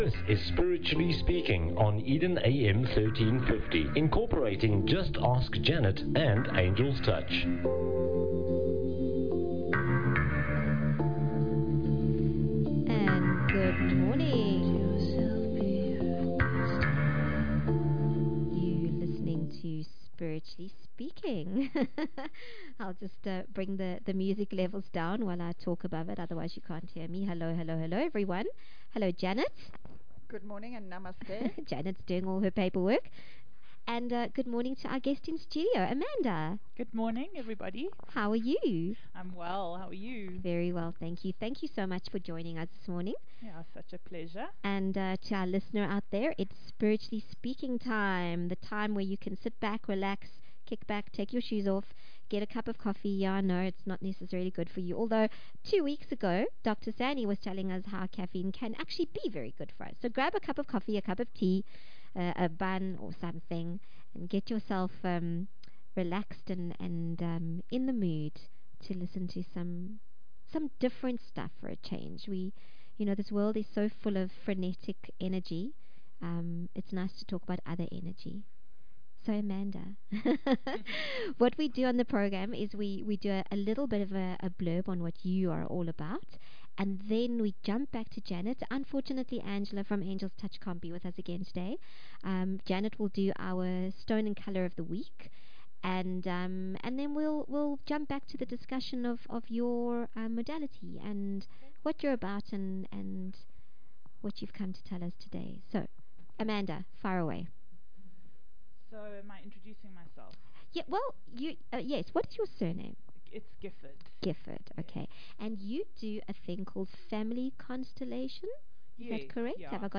Radio Interviews and Media Channel.